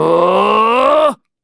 Kibera-Vox_Casting3.wav